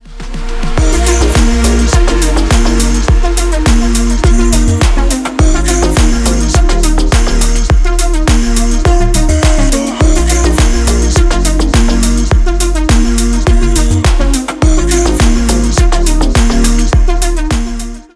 • Качество: 320, Stereo
deep house
веселые
без слов
инструментальные